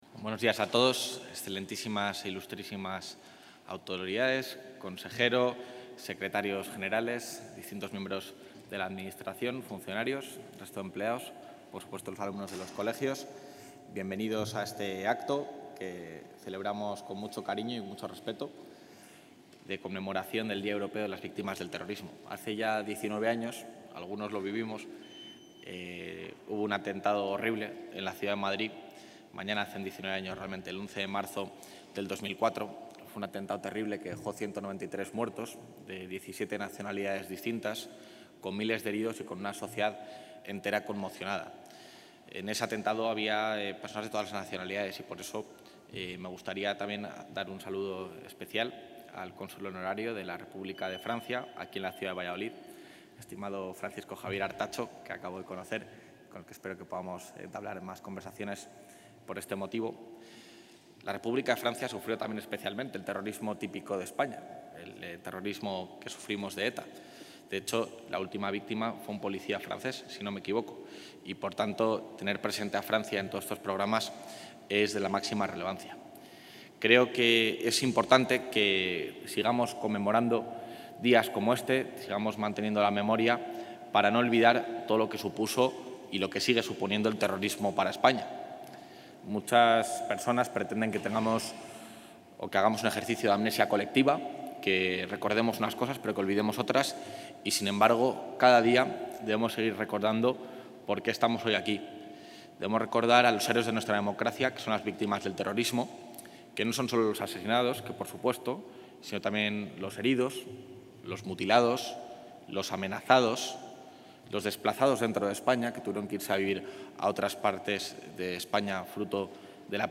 Intervención del vicepresidente de la Junta.
Con motivo del 19.º Día Europeo en Recuerdo a las Víctimas del Terrorismo, el vicepresidente de la Junta de Castilla y León, Juan García-Gallardo, ha presidido en Valladolid el acto institucional en el que el Gobierno autonómico ha conmemorado a las víctimas del terrorismo en todo el mundo, una fecha que coincide con el terrible ataque terrorista perpetrado en Madrid el 11 de marzo de 2004, en el que fueron asesinadas 192 personas y 1.893 resultaron heridas.